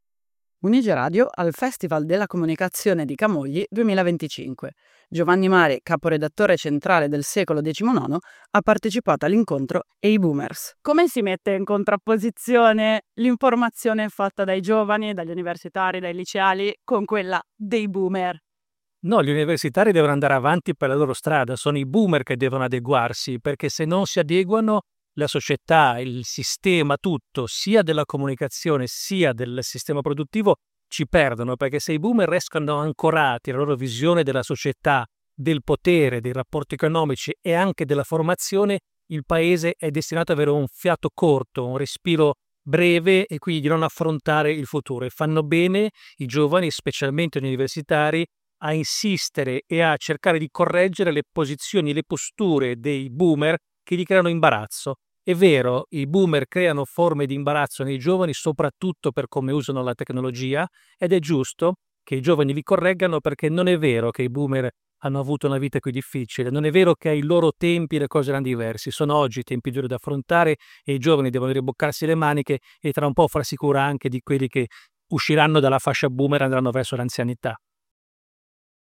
UniGE al Festival della Comunicazione 2025